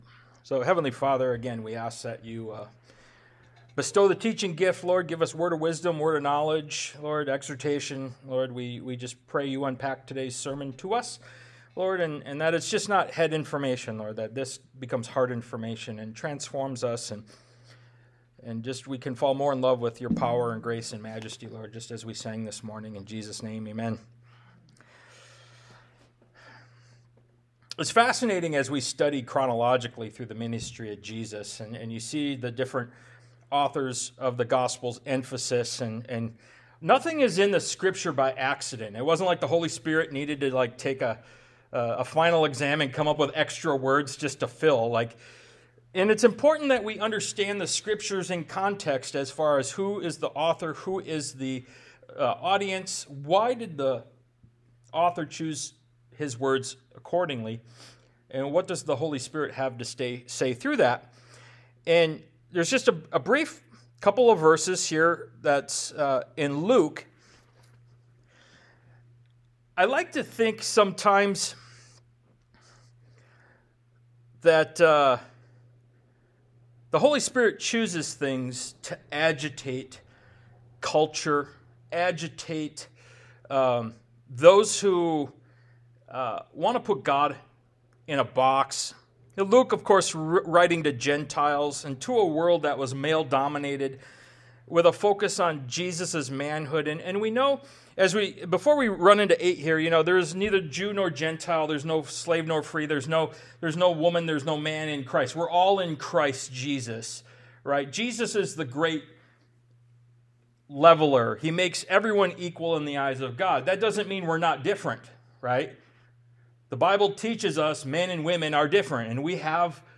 Ministry of Jesus Service Type: Sunday Morning « “Are You Burdened?”